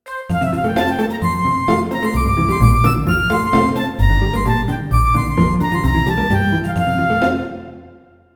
Tonalidad de Fa mayor. Ejemplo.
desenfadado
festivo
jovial
melodía
sintetizador
Sonidos: Música